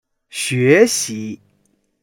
xue2xi2.mp3